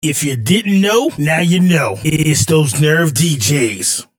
Acapellas